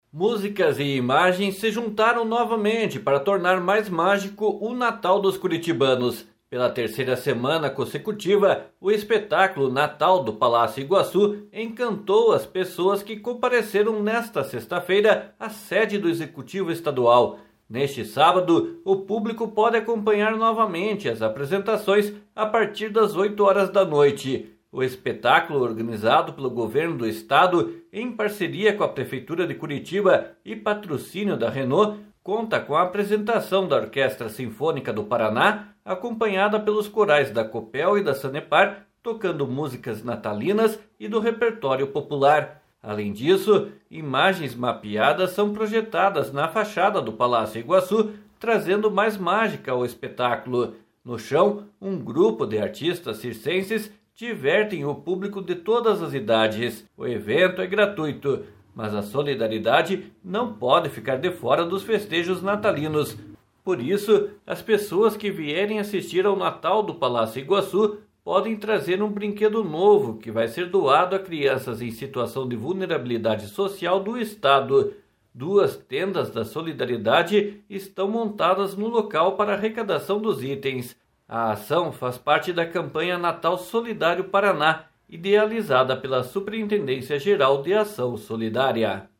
O espetáculo organizado pelo Governo do Estado, em parceria com a prefeitura de Curitiba e patrocínio da Renault, conta com apresentação da Orquestra Sinfônica do Paraná, acompanhada pelos corais da Copel e da Sanepar, tocando músicas natalinas e do repertório popular.